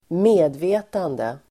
Uttal: [²m'e:dve:tande]